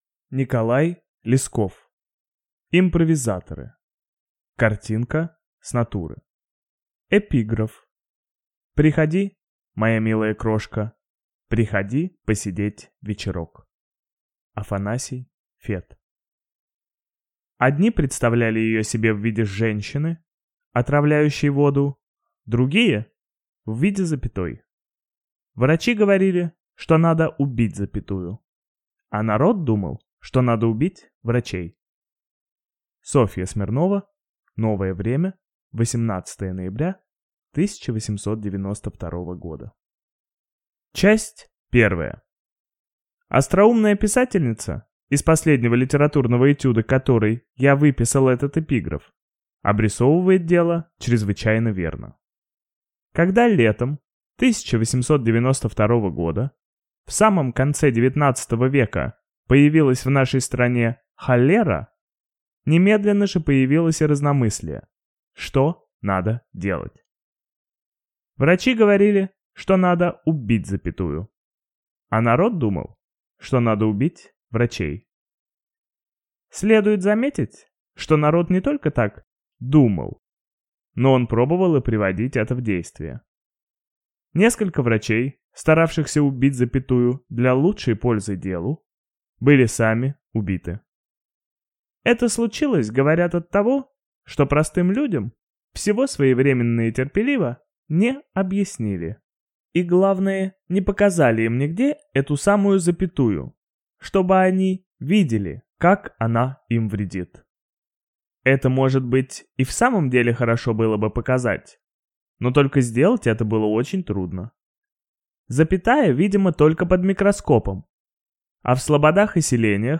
Аудиокнига Импровизаторы | Библиотека аудиокниг
Читает аудиокнигу